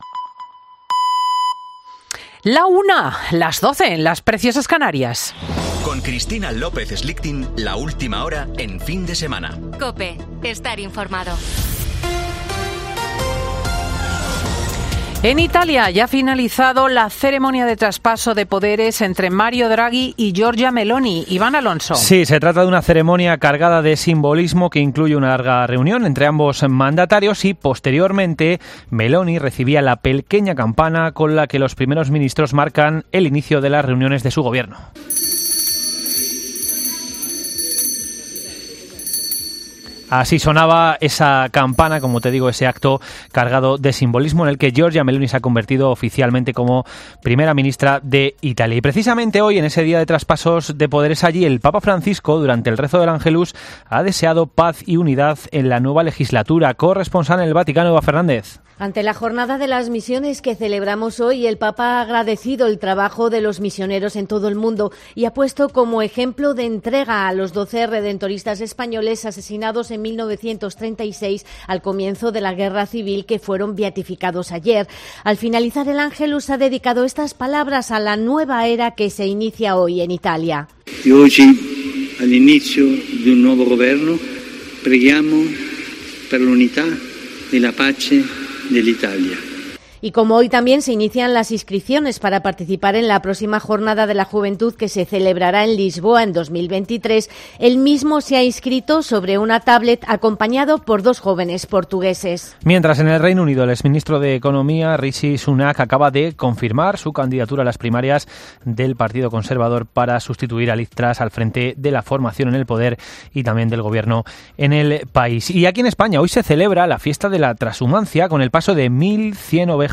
AUDIO: Boletín de noticias de COPE del 23 de octubre de 2022 a las 13.00 horas